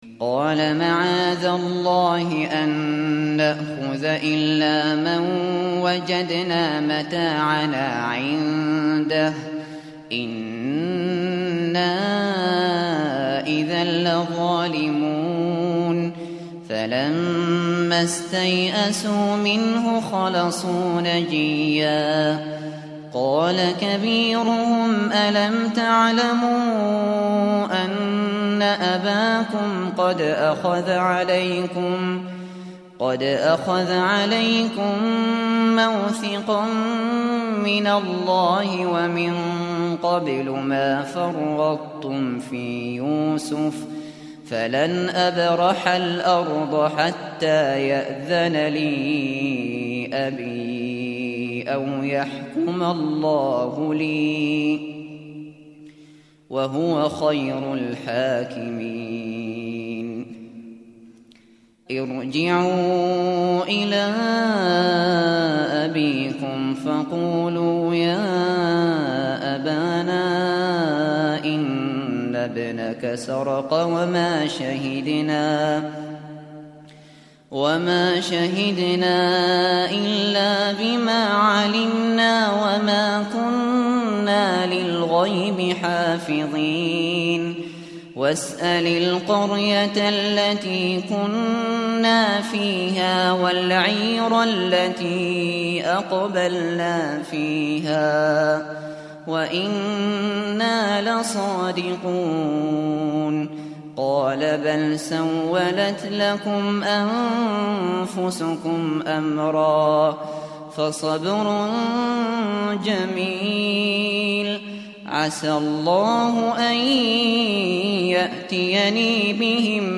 Kur'ân dinlemeye başlamak için bir Hafız seçiniz.
Hafız Abu Bakr al Shatri sesinden Cüz-13, Sayfa-245 dinle!
Hafız Maher Al Mueaqly sesinden Cüz-13, Sayfa-245 dinle!
Hafız Mishary AlAfasy sesinden Cüz-13, Sayfa-245 dinle!